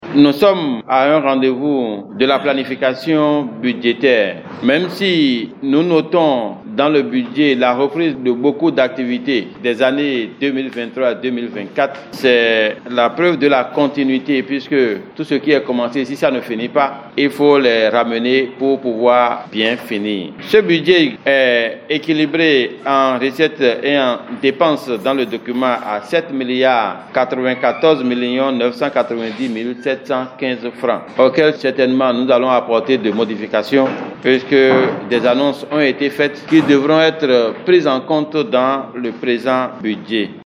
C’est à la faveur de la 4e session ordinaire du conseil communal de la ville de l’espérance au titre de 2024 tenue ce mardi 29 octobre.
SESSION-BUDGETAIRE-LOKOSSA-1.mp3